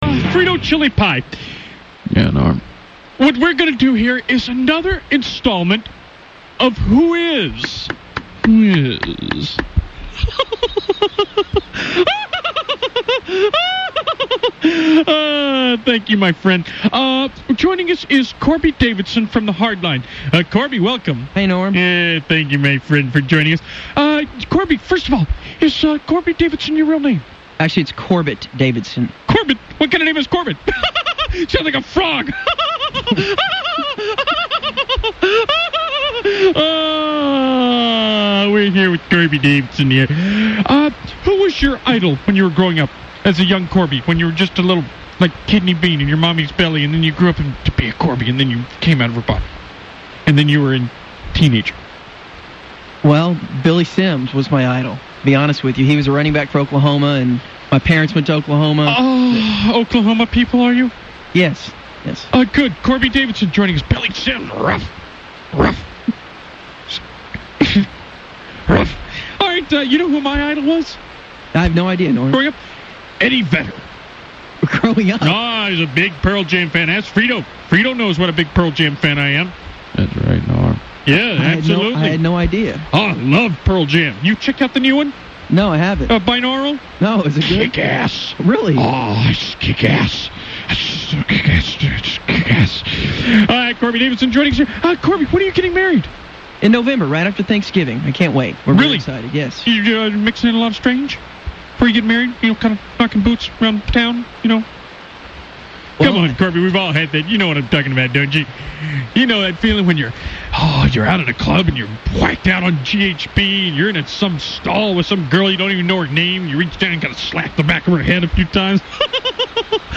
laughs his way through a conversation